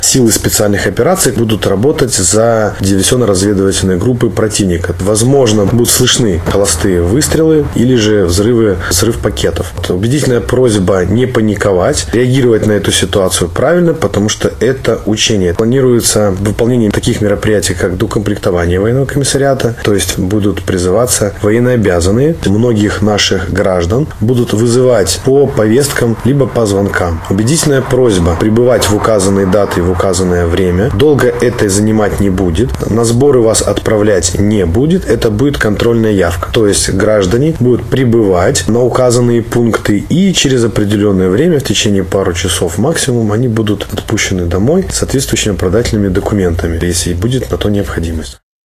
В связи с этим местные жители могут слышать звуки холостых выстрелов. Паниковать не стоит – это плановые учения, — рассказал военный комиссар города Барановичи, Барановичского и Ляховичского районов Алексей Синчук.